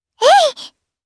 Lavril-Vox_Attack2_jp.wav